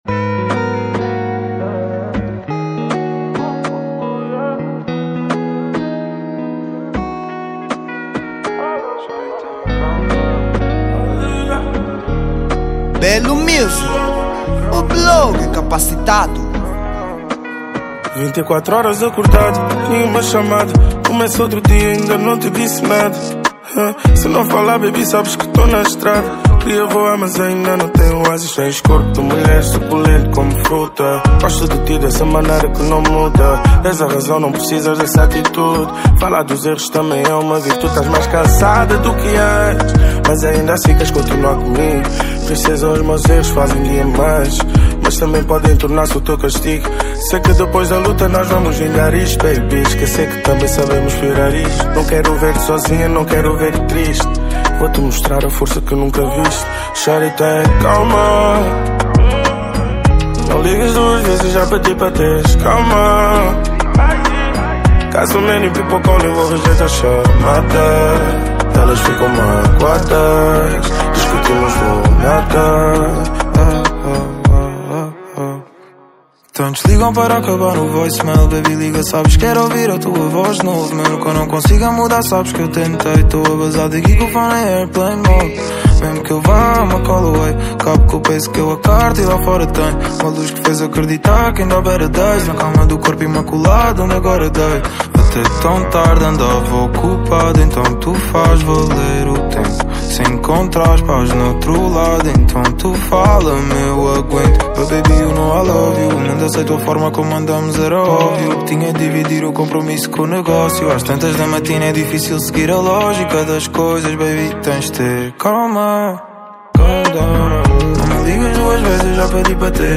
Género: Afro beats